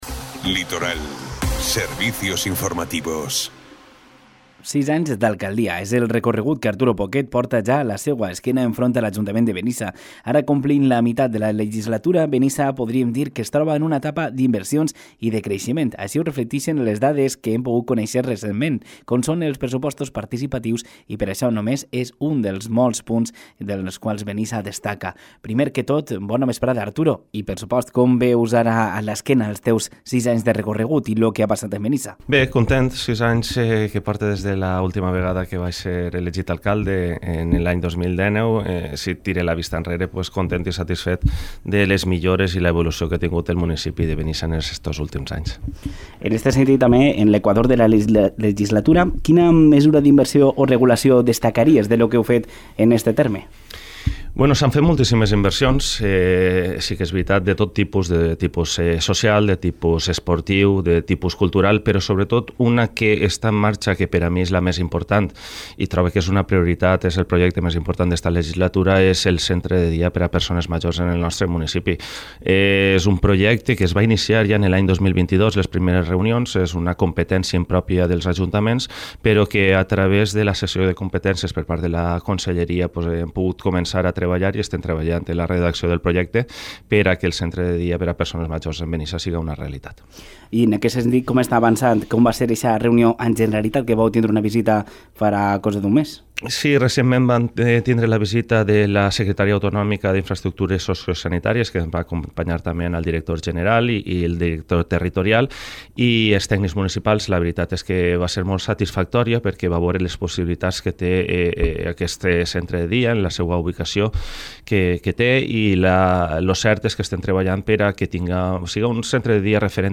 Por esa misma razón, Arturo Poquet ha acudido al Informativo de Ràdio Litoral, en la edición de este jueves 12 de junio, para hacer balance de la gestión del equipo de gobierno.